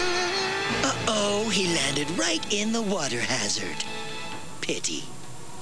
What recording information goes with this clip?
Clips taken out of the Dragon Ball Z show.